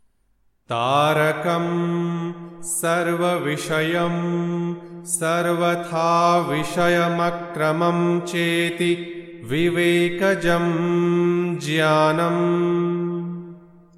Sutra Chanting